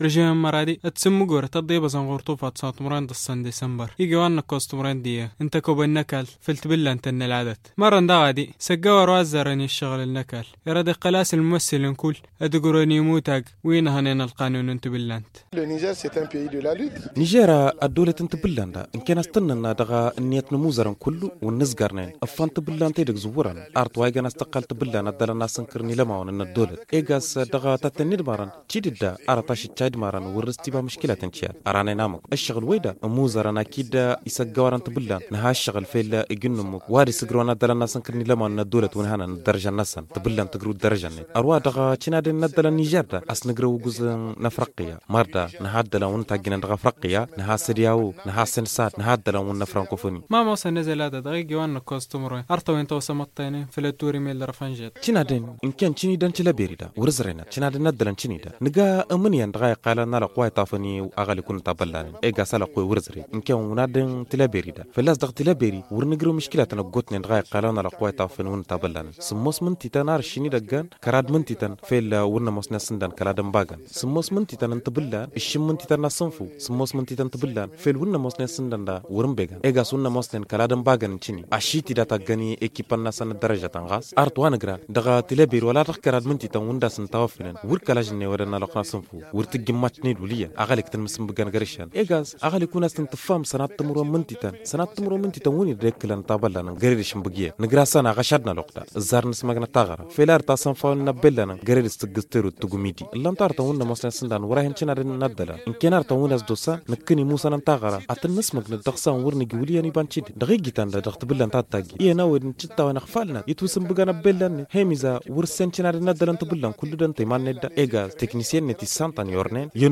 [Magazine] Les règles au 41ème championnat de lutte au Niger - Studio Kalangou - Au rythme du Niger